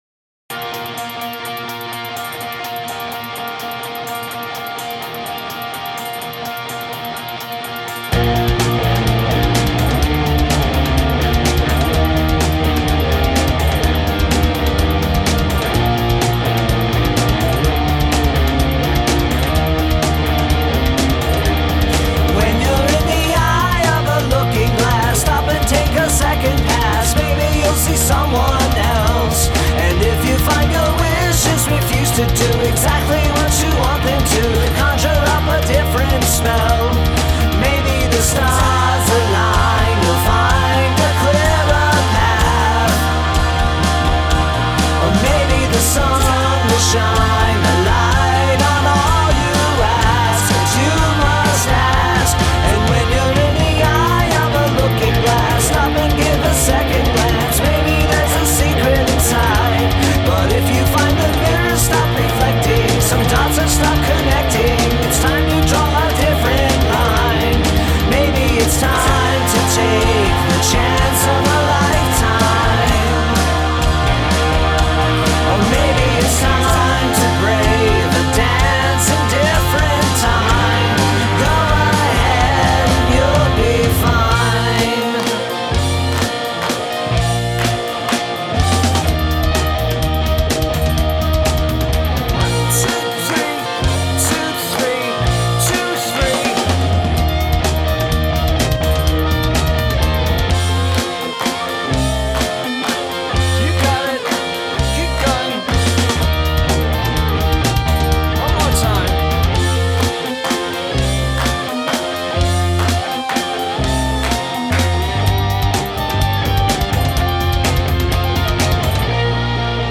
Waltz
The waltz bits were pretty cool.